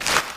STEPS Newspaper, Walk 02.wav